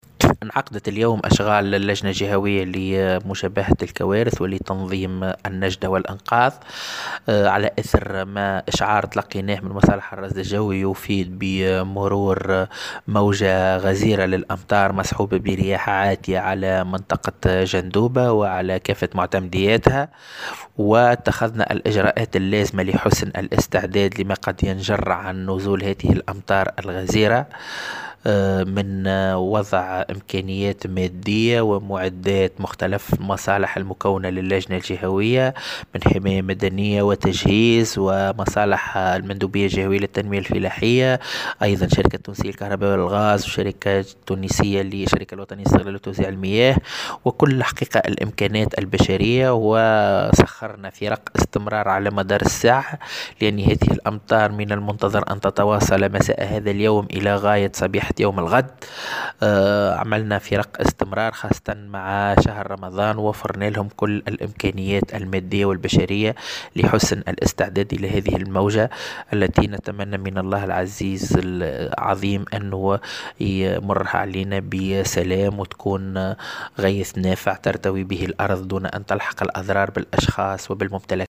أكد والي جندوبة أكرم السبري في تصريح لمراسل الجوهرة "اف ام" ان اللجنة الجهوية لمجابهة الكوارث انعقدت اليوم الإثنين بعد تلقيها اشعارا من مصالح الرصد الجوي يفيد بمرور موجة غزيرة من الأمطار مصحوبة برياح قوية على جندوبة وكافة معتمدياتها.